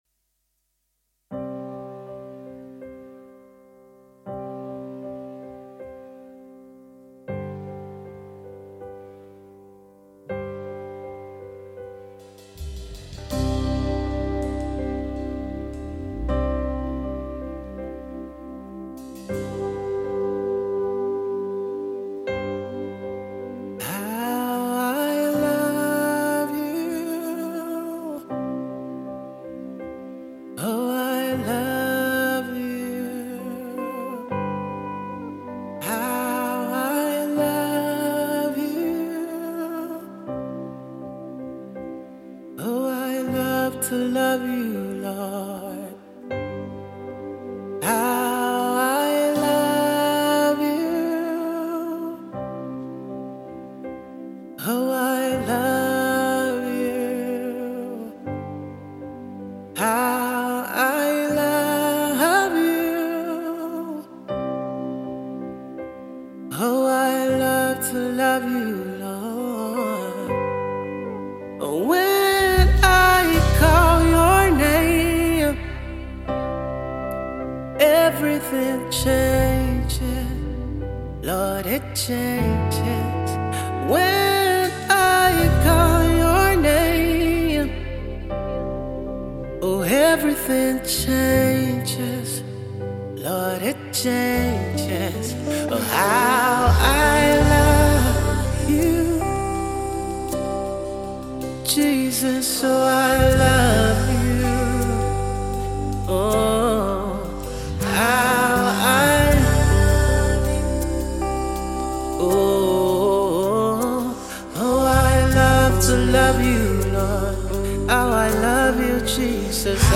Drums
Keys
Strings
Guitar
Bass
Alto
Tenor
Soprano